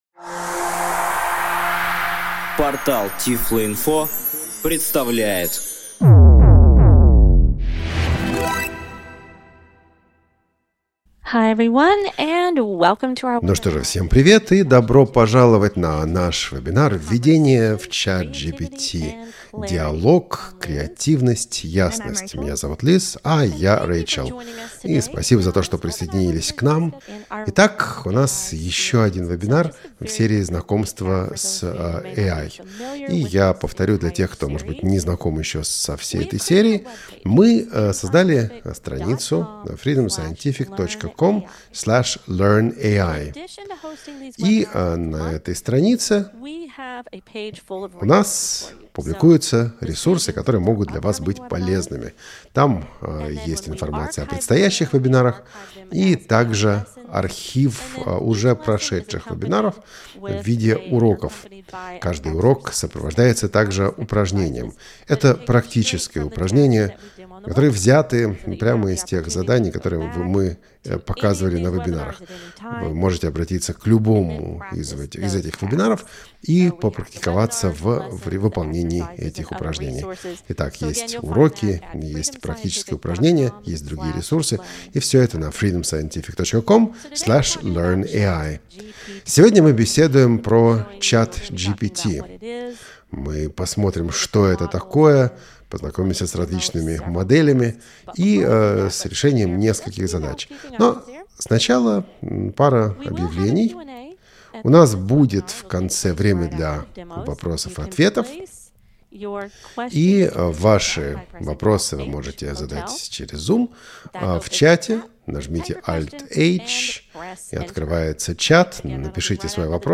Сотрудники компании Vispero представили обзор возможностей ChatGPT и показали пошаговый пример из реальной рабочей практики.